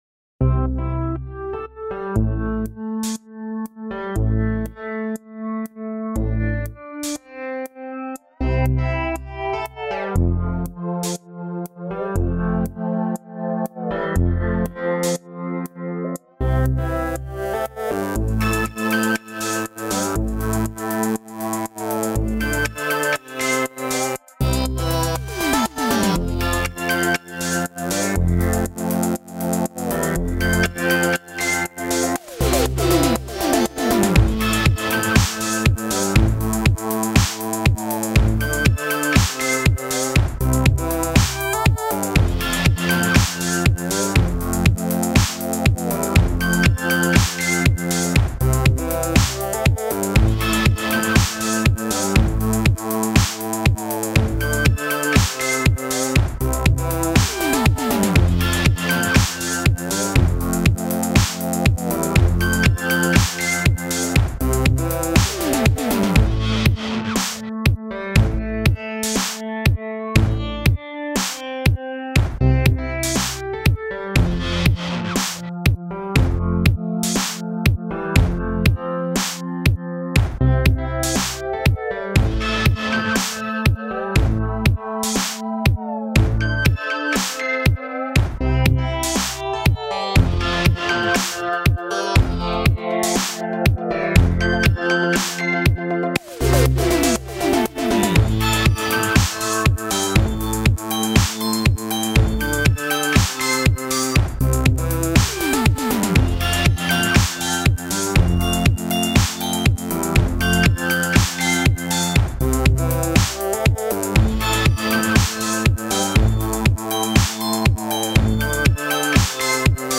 Filed under: Instrumental | Comments (3)
nice and danceable. I love the drum rolls and said pulsiness.